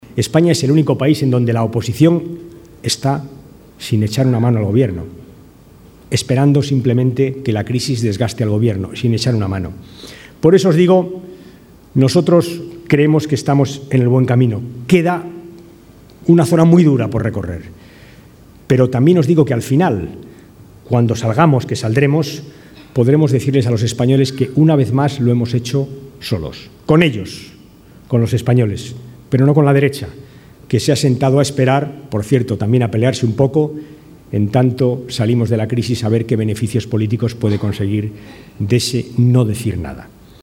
Denuncia, en un acto público en Ciudad Real, que “algún gurú de la demoscopia política” le ha dicho a Rajoy “no te pringues, esto de la crisis que lo paguen los socialistas”